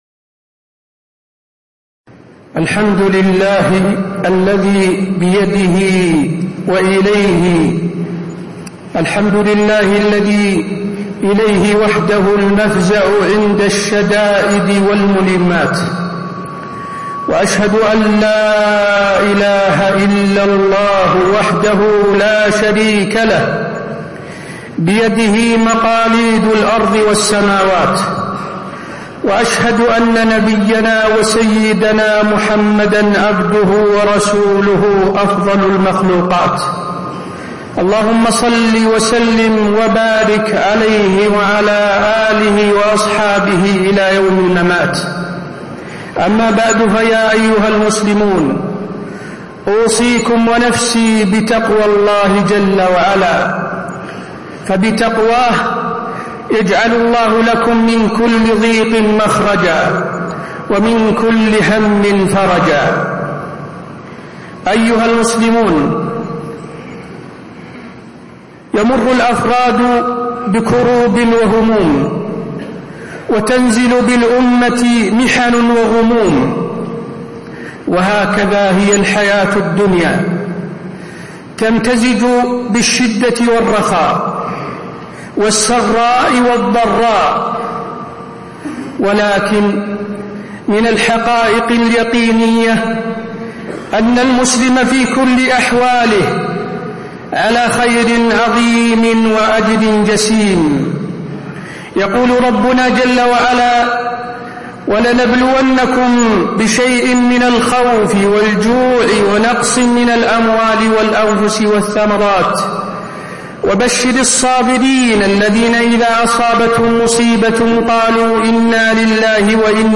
تاريخ النشر ١٧ جمادى الأولى ١٤٣٧ هـ المكان: المسجد النبوي الشيخ: فضيلة الشيخ د. حسين بن عبدالعزيز آل الشيخ فضيلة الشيخ د. حسين بن عبدالعزيز آل الشيخ الهموم والغموم ووسائل دفعها